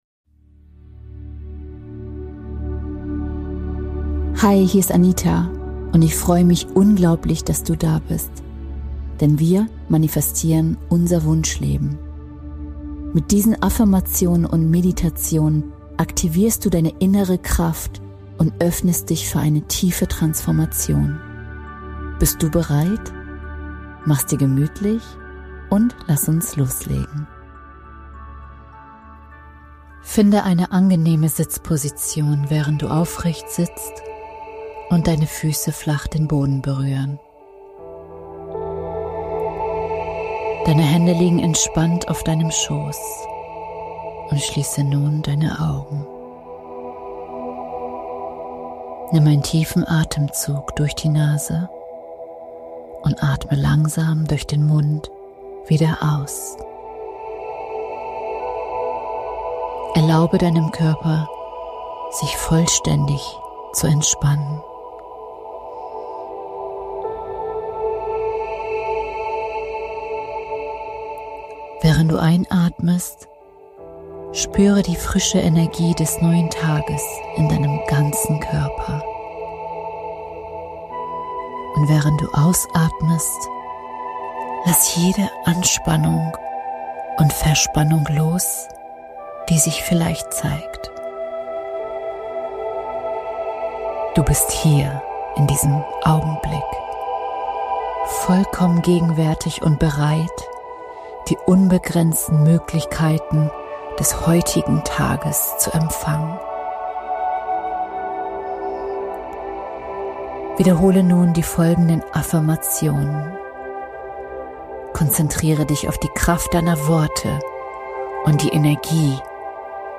Eine Meditation, die dich am Morgen in deine Kraft führt – damit du voller Vertrauen und Weite in den Tag gehst und empfängst, was dir zusteht. Ein tägliches Ritual für mehr Erfolg, Selbstbewusstsein und innere Fülle.